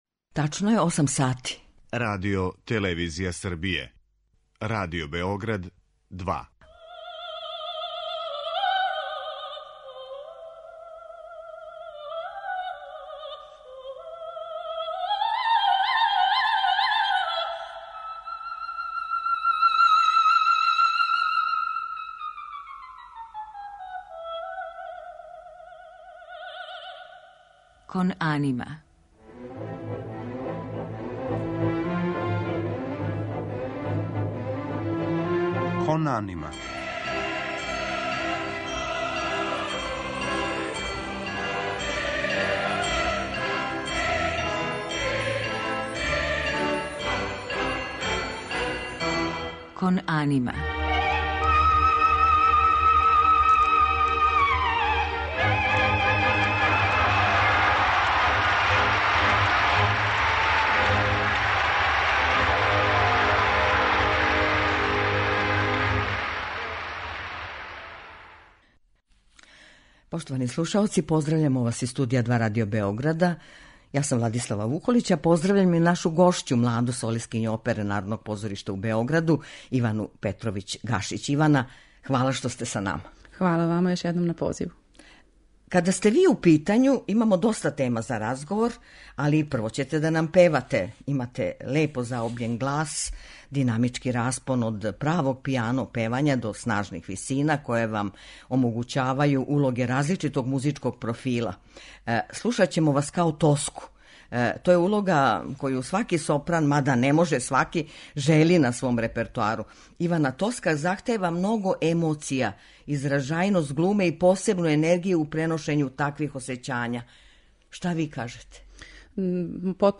У музичком делу биће емитоване арије из опера Ђакома Пучинија и Ђузепа Вердија у тумачењу ове наше младе и цењене вокалне уметнице.